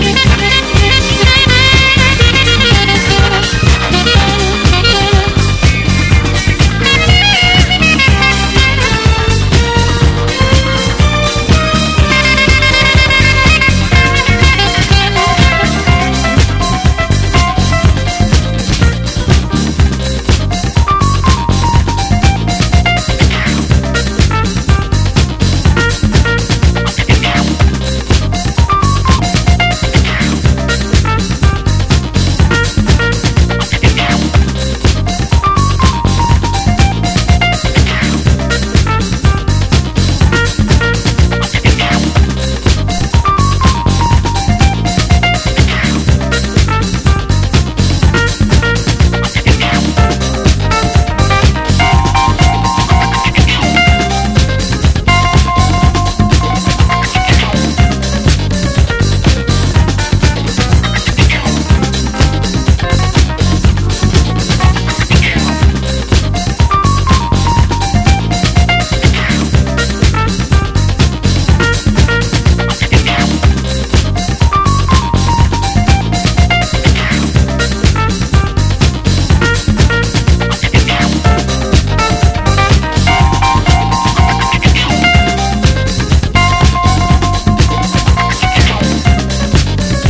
SOUL / SOUL / 70'S～ / DISCO / SAMPLING SOURCE / PHILLY SOUL